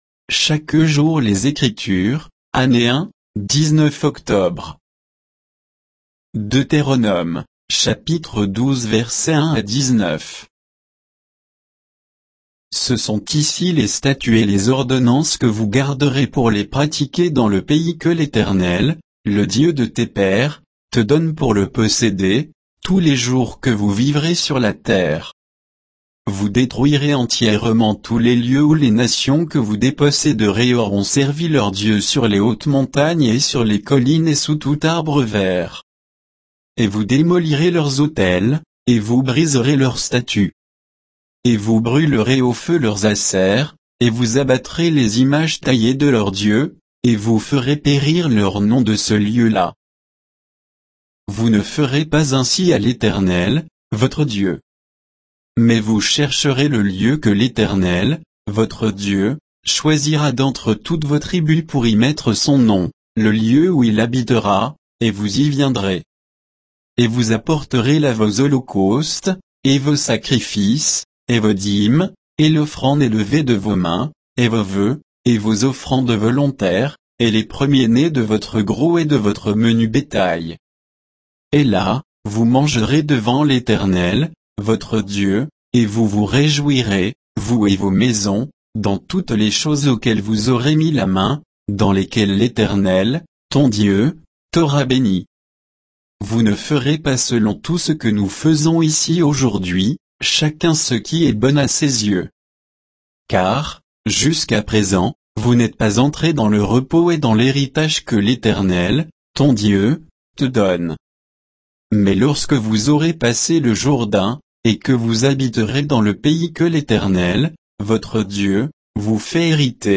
Méditation quoditienne de Chaque jour les Écritures sur Deutéronome 12, 1 à 19